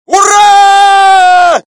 Здесь вы найдете громкие призывы воинов, ритуальные возгласы и современные мотивационные крики.
Боевой клик парня